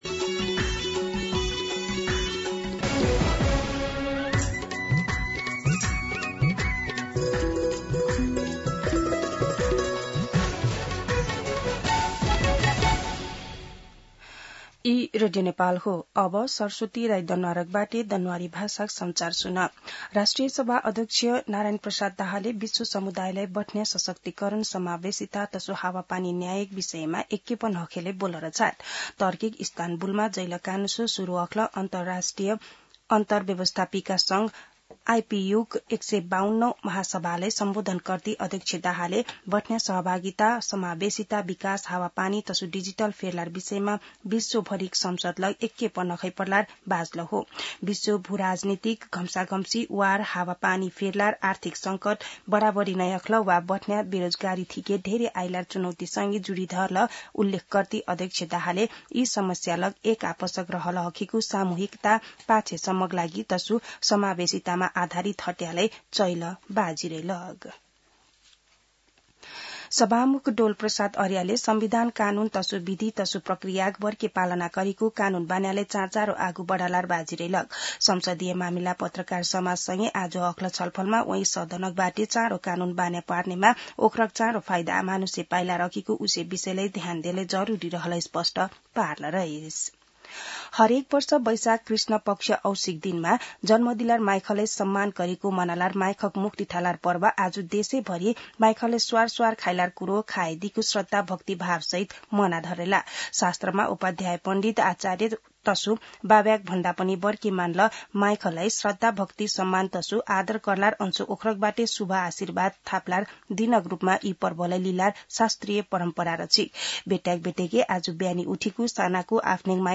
दनुवार भाषामा समाचार : ४ वैशाख , २०८३
Danuwar-News-1-4.mp3